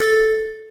Techmino/media/effect/chiptune/warn_1.ogg at fd3910fe143a927c71fbb5d31105d8dcaa0ba4b5
warn_1.ogg